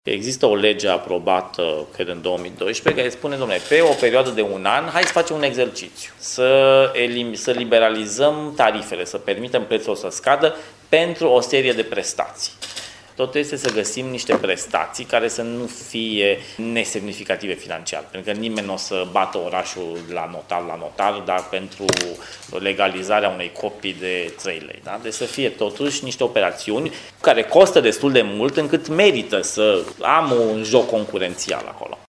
Preşedintele Consiliului Concurenţei, Bogdan Chiriţoiu, a declarat la Timişoara, că în perioada următoare se vor stabili detaliile proiectui, care se va derula pe parcursul unui an.